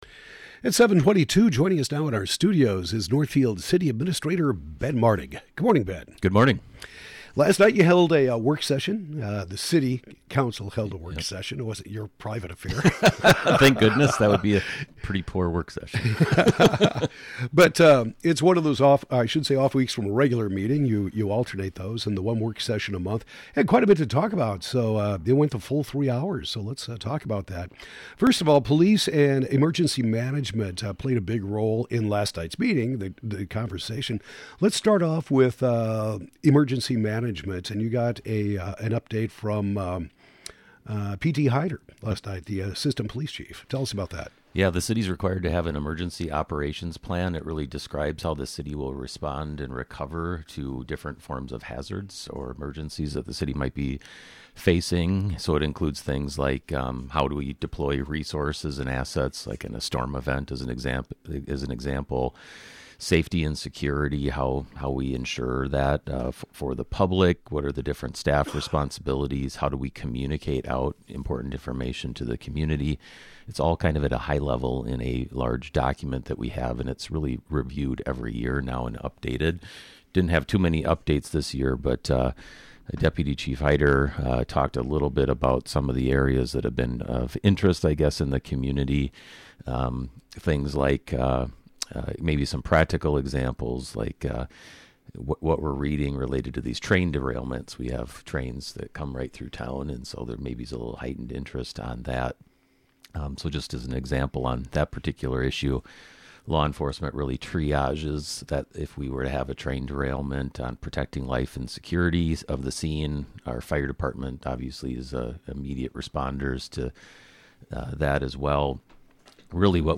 Northfield City Administrator Ben Martig talks about the May 9th City Council Work Session